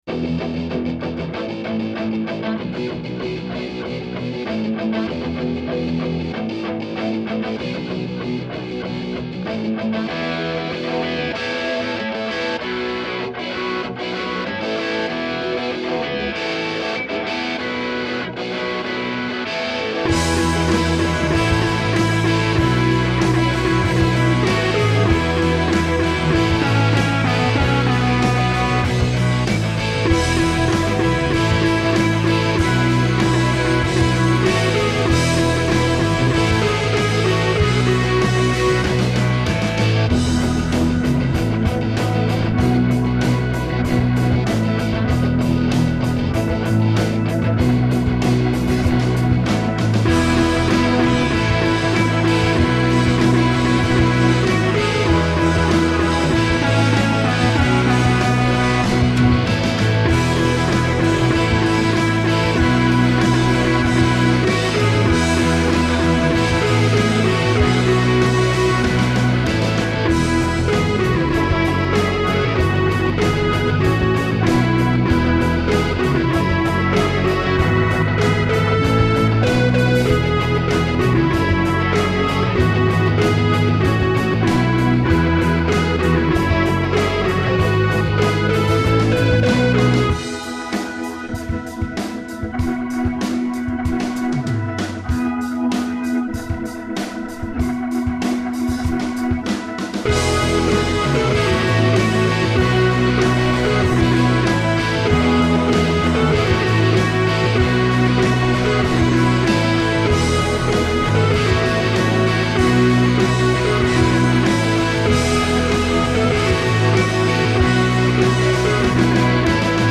Praise and Worship song